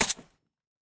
sounds / weapons / _bolt / 556_2.ogg